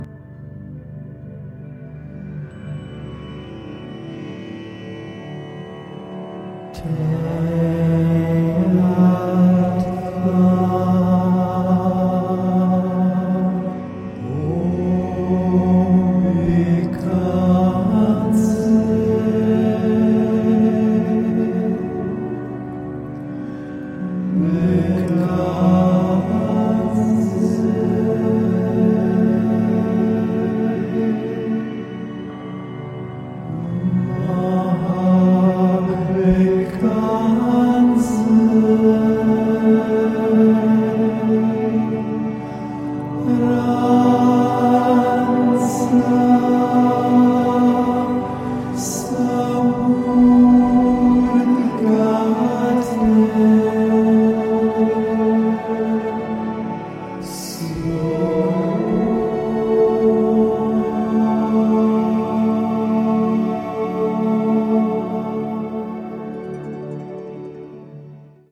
recorded in 432 Hz Healing Frequency